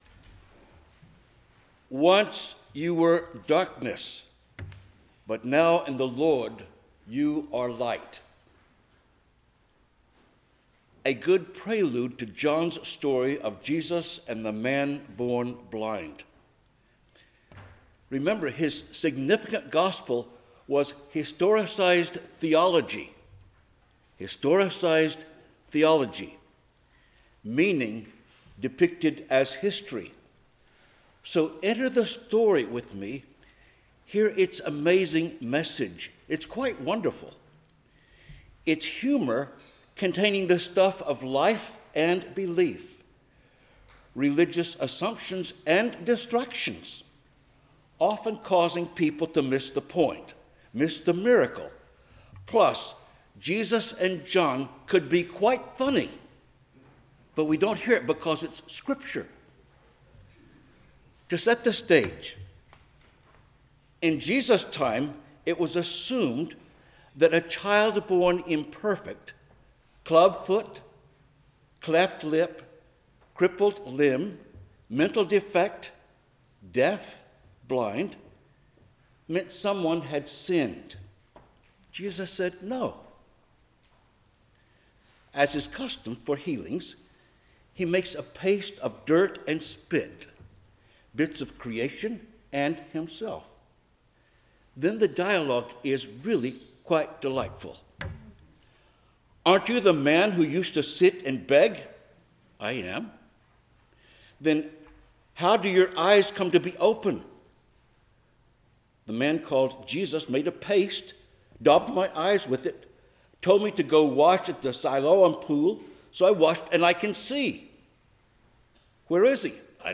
Passage: 1 Samuel 16:1-13, Psalm 23, Ephesians 5:8-14, John 9:1-41 Service Type: 10:00 am Service